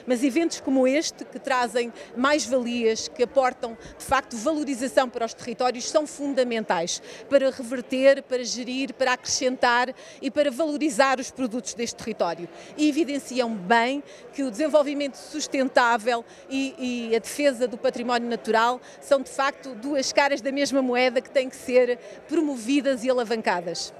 A cerimónia de inauguração foi presidida por Sandra Sarmento, diretora regional do Instituto da Conservação da Natureza e das Florestas (ICNF), que sublinhou que eventos como este representam dois benefícios em simultâneo — a valorização do património natural e o desenvolvimento local: